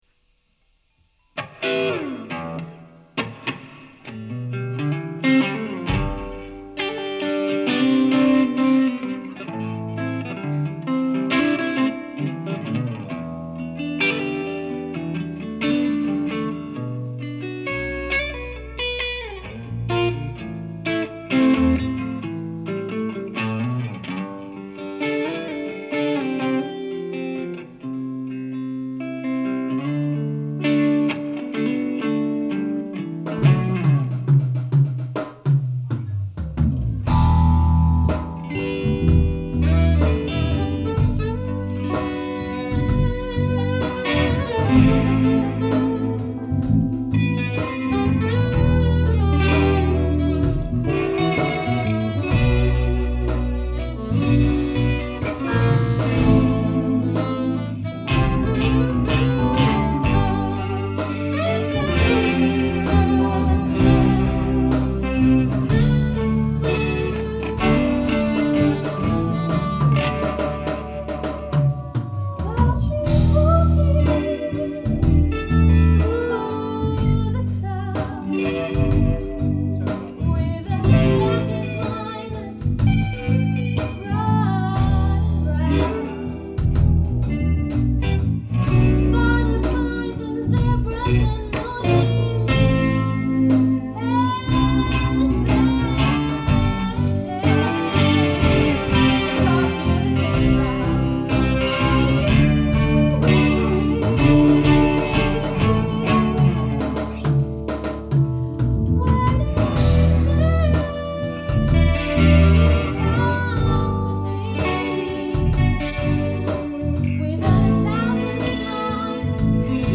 Gonna leave you with the recording I made of Little Wing during our rehearsal today.